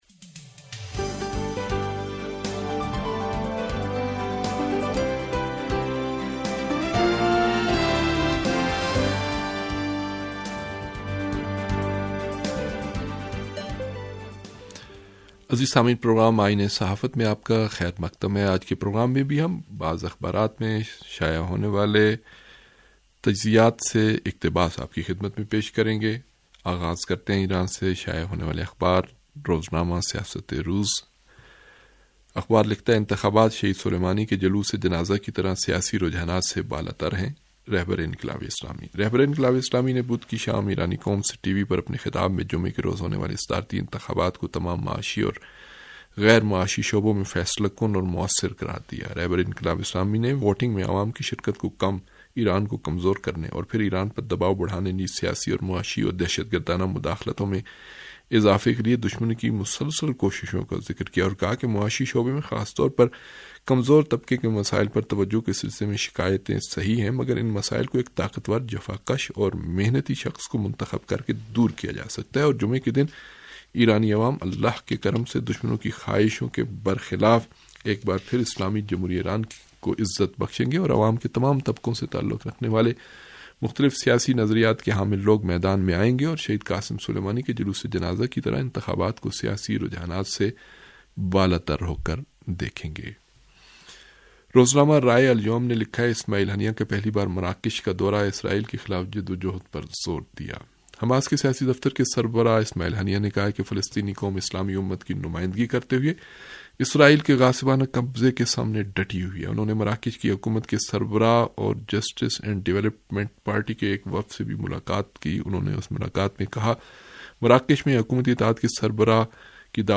ریڈیو تہران کا اخبارات کے جائزے پرمبنی پروگرام آئینہ صحافت